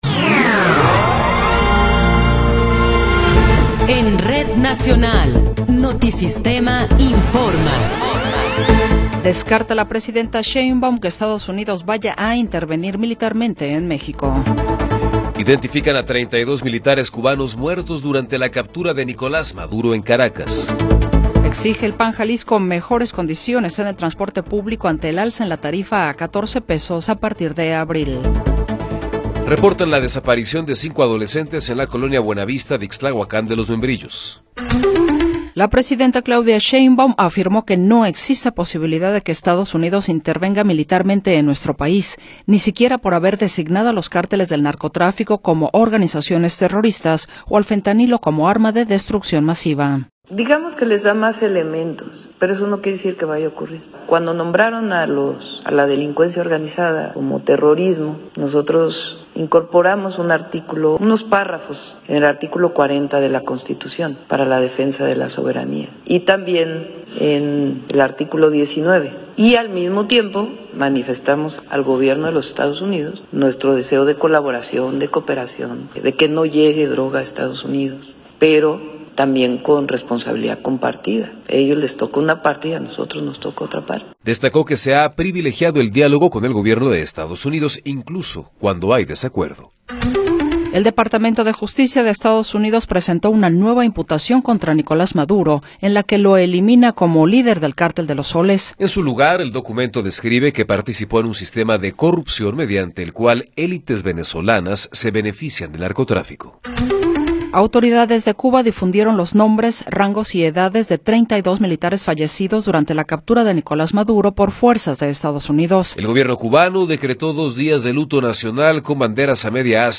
Noticiero 14 hrs. – 6 de Enero de 2026